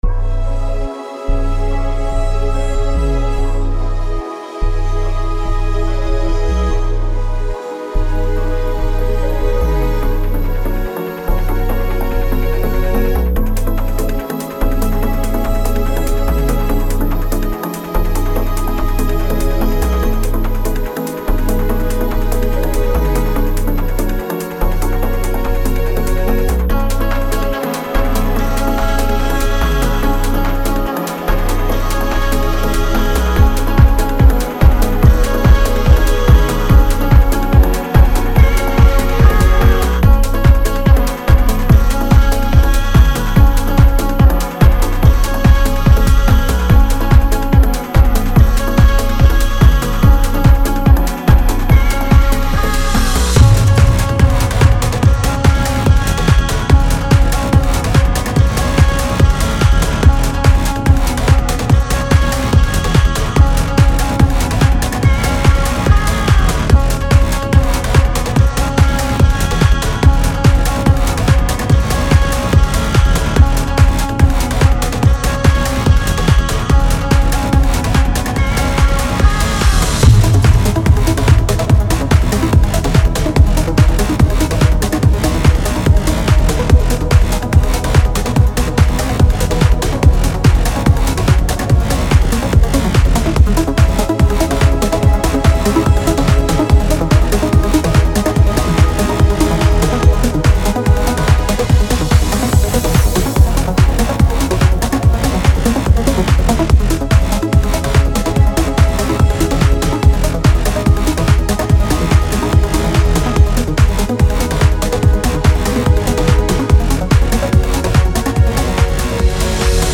This is the instrumental.
Tempo 144BPM (Allegro)
Genre Euphoric Trance
Type Instrumental
Mood energetic